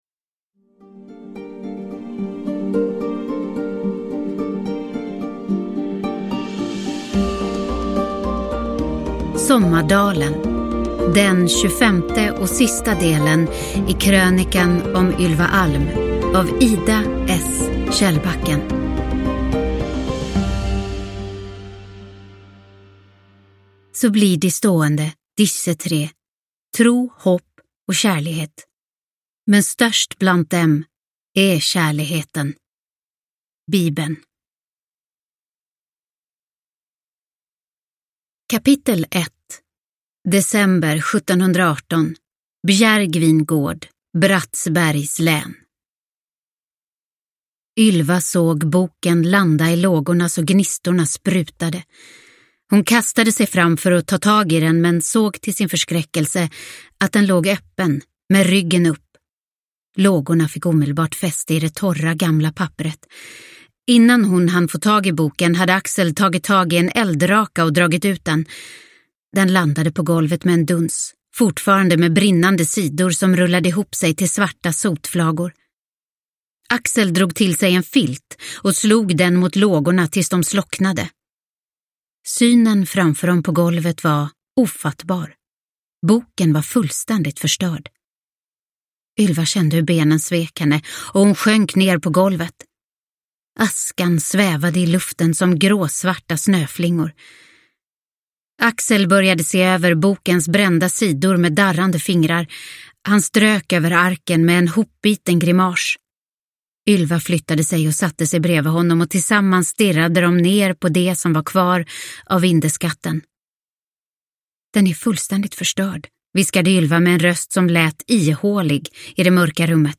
Sommardalen – Ljudbok – Laddas ner